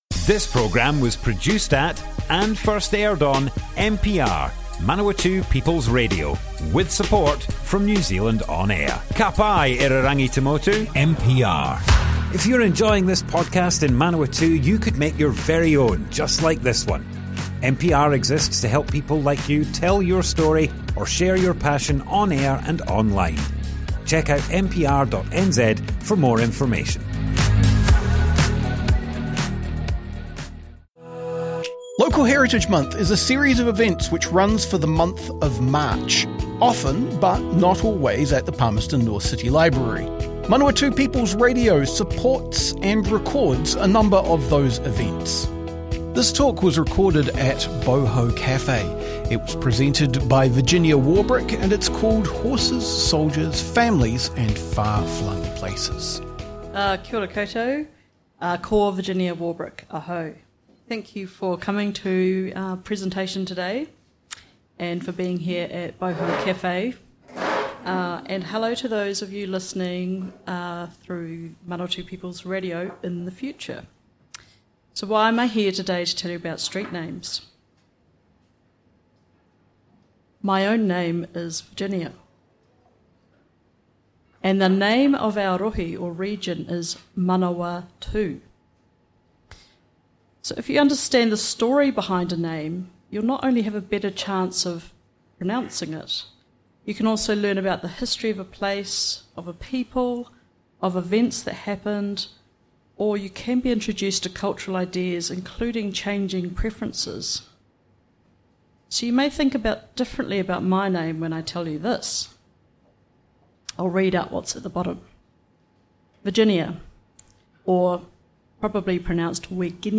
This talk was delivered as part of Heritage Month, in March 2025. The talk was delivered at Boho Cafe, at an event supported by River Stop Awapuni and recorded live by MPR.